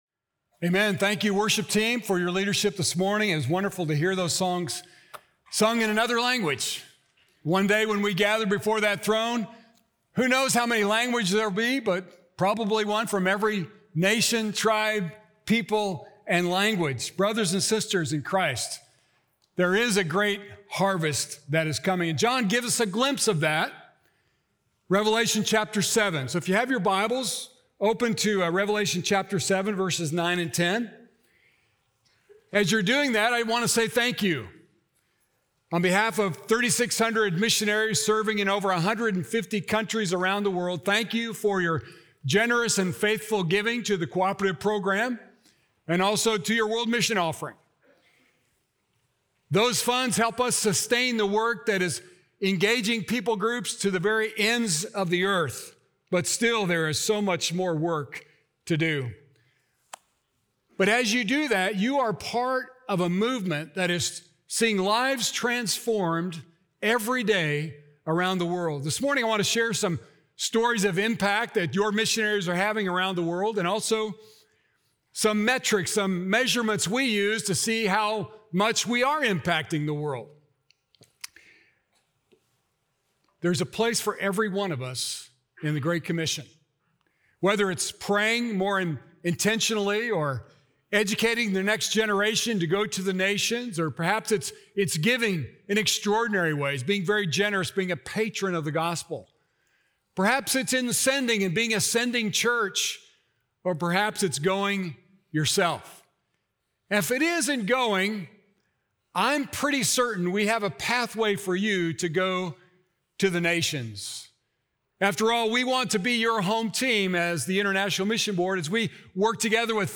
A Great Harvest is Coming! - Sermon - Ingleside Baptist Church